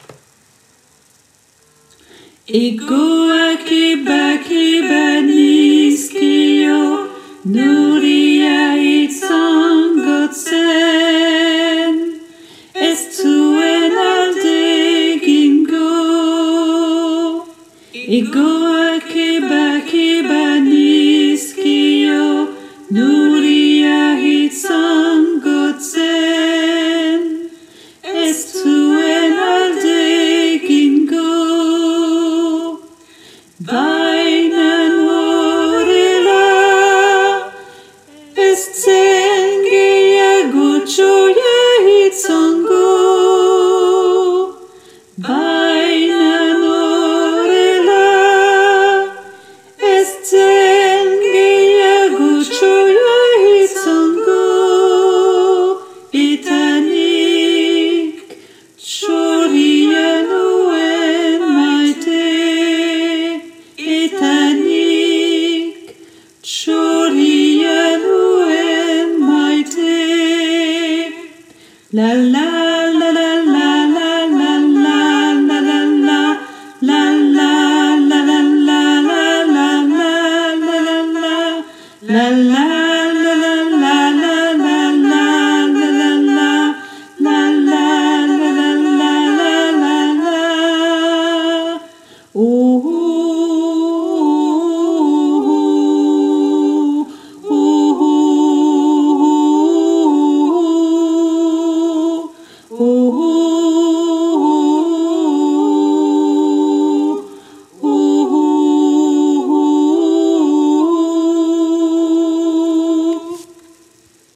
- Chant pour choeur à 4 voix mixtes (SATB)
Alto Et Autres Voix En Arriere Plan